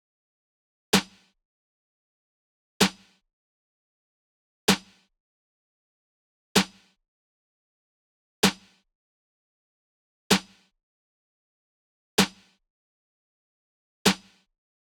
CHANGE$ SNARE.wav